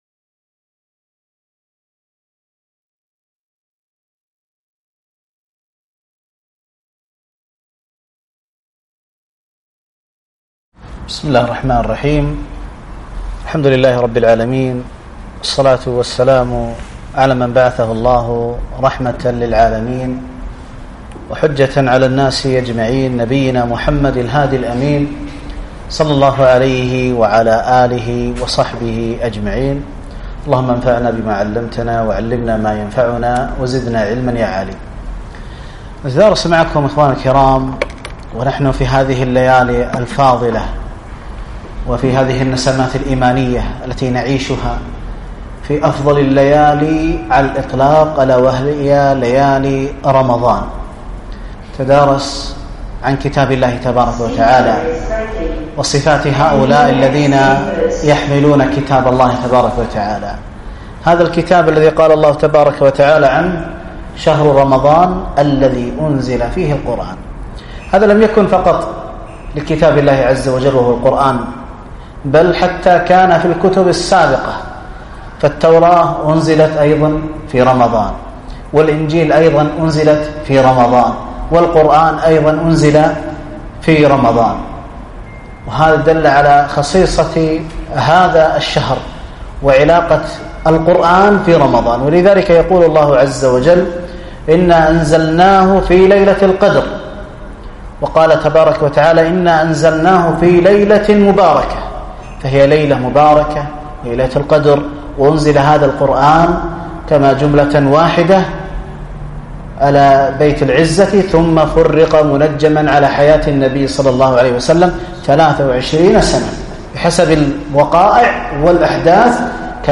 كلمة - صفات أهل القرآن
ألقيت بعد التراويح